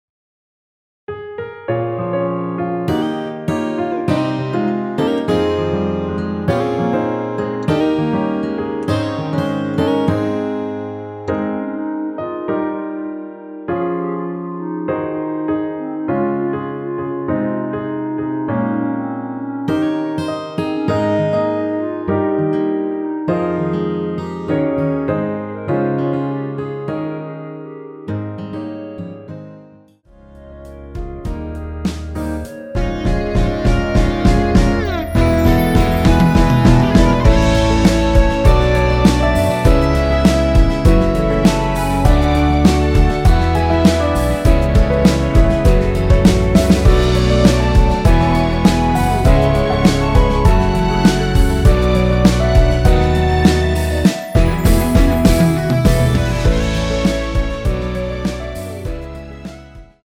원키에서(-1)내린 멜로디 포함된 MR입니다.(미리듣기 확인)
Ab
앞부분30초, 뒷부분30초씩 편집해서 올려 드리고 있습니다.